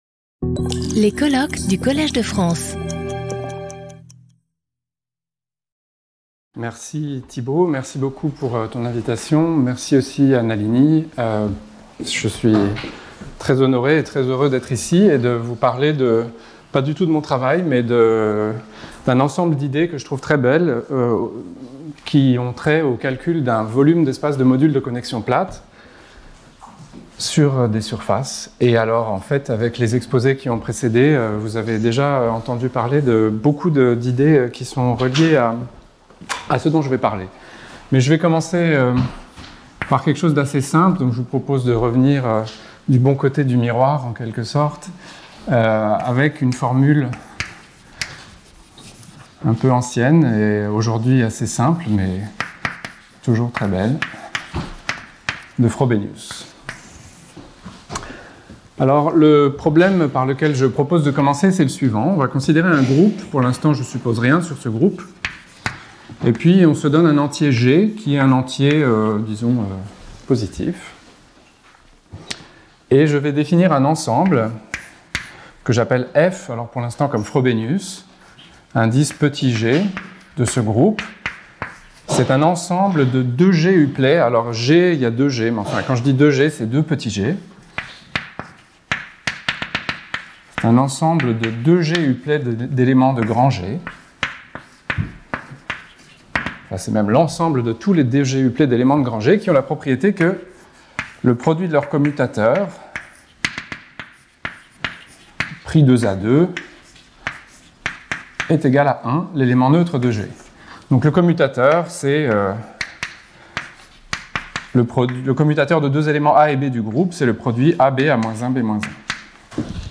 In this talk, I will explain how Witten, at the beginning of the 1990's, computed the symplectic volume of the moduli space of flat connections on a principal bundle over a closed compact surface.